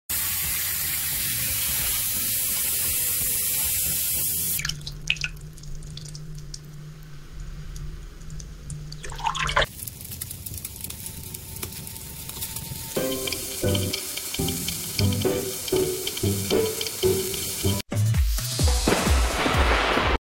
ASMR Giặt, Sấy, ủi 1 Sound Effects Free Download